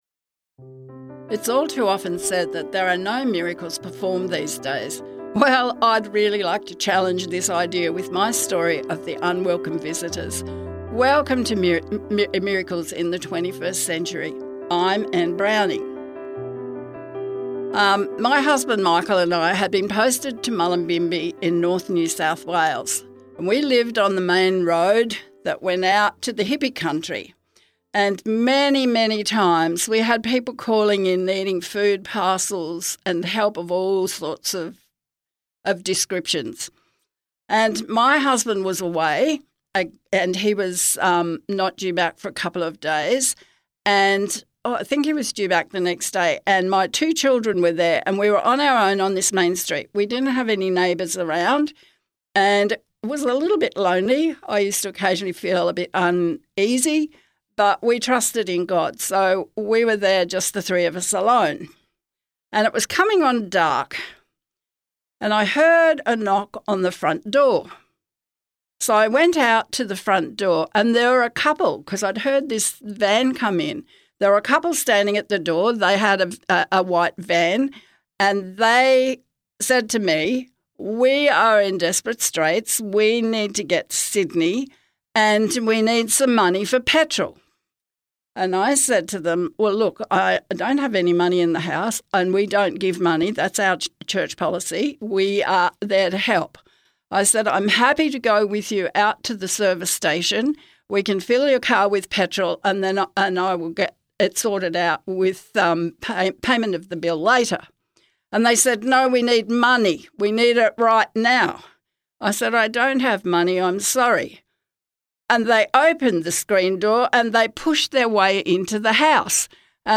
Music Credits: